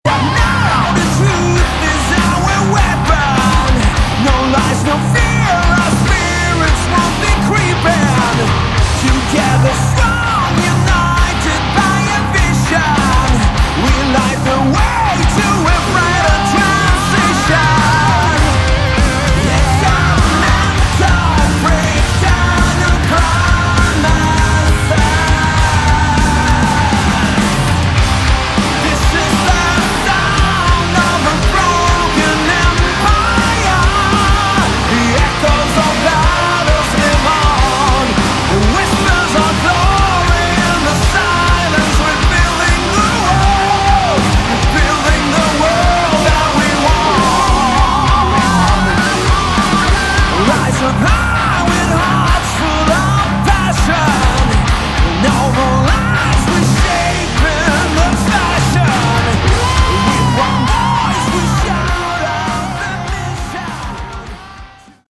Category: Hard Rock / Melodic Metal
Guitars
Vocals
Bass
Keys
Drums